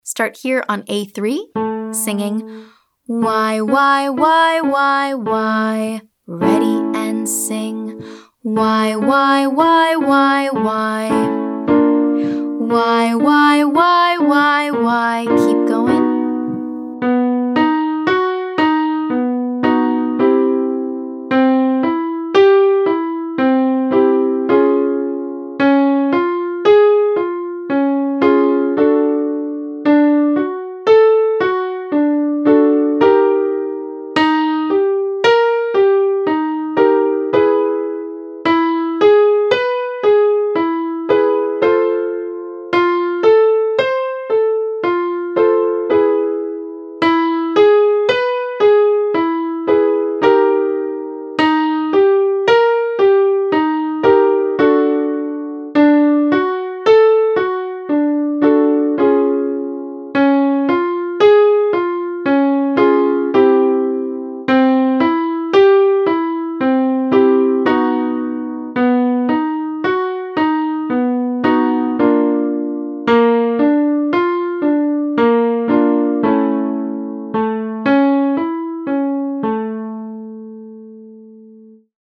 In Part 2, we’ll focus on achieving a chest-dominant mix to retain the strength of your chest voice while accessing higher pitches.
Then, we’ll try both “WHY” and “WOW” to see which vowel sounds best for your voice.
Essential Belting Warmup for High Voice 3B